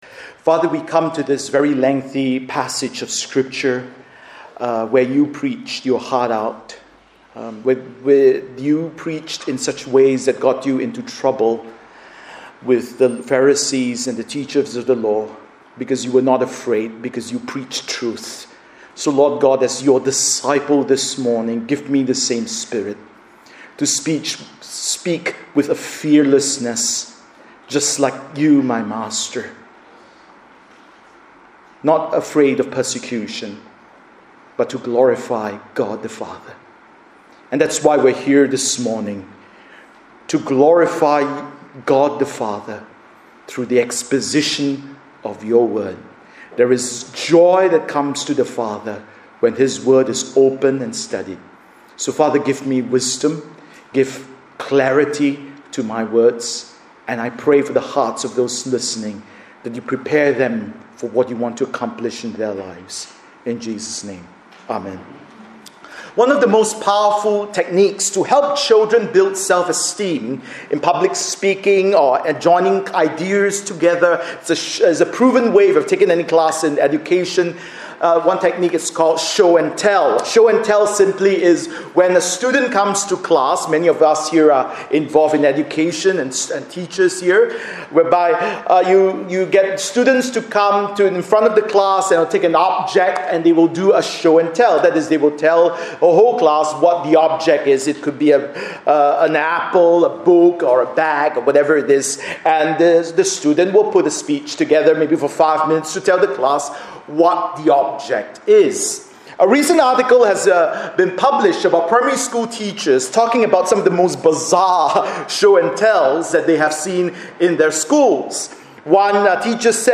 Bible Text: Matthew 5:1-12 | Preacher